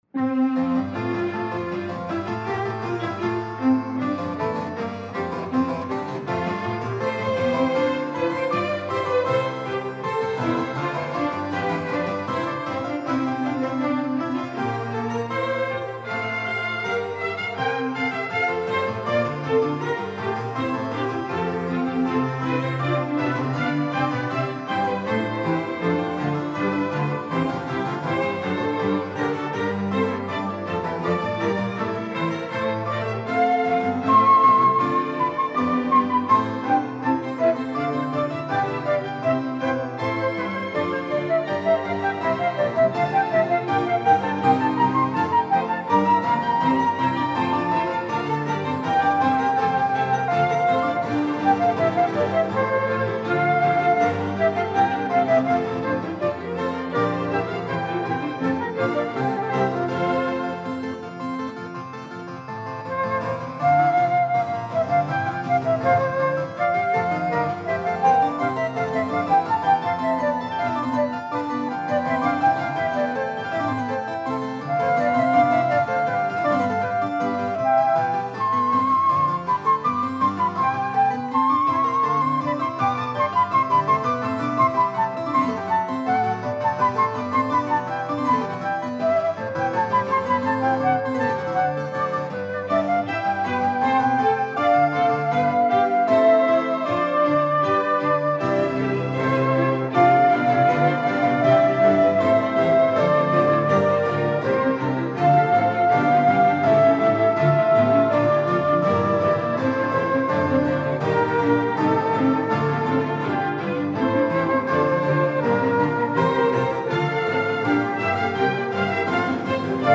Allegro Assai